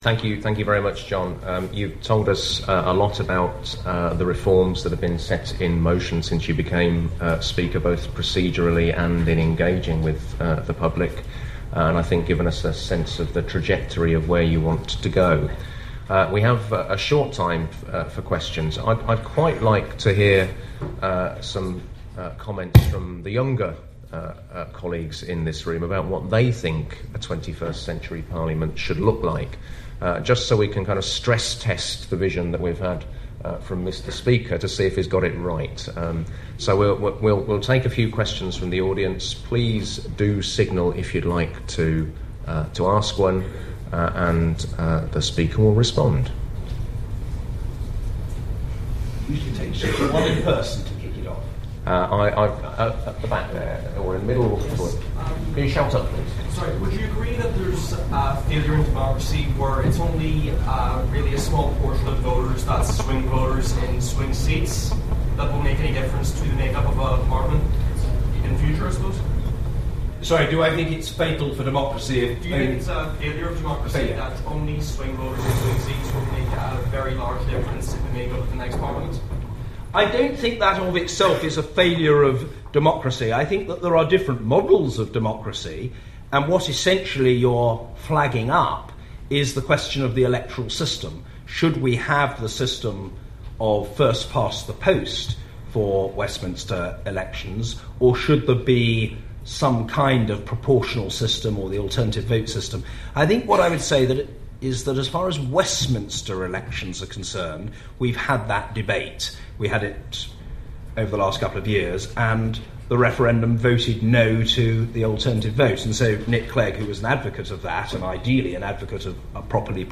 John Bercow taking questions after his lecture
John Bercow delivered the closing lecture at the Political Studies Association 2012 conference in Belfast.